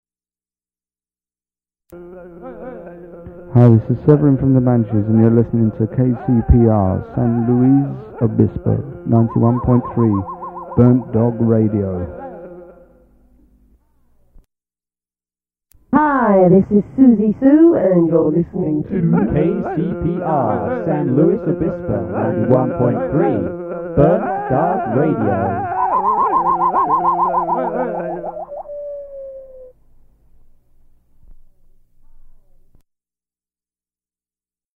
station identification
Form of original Audiocassette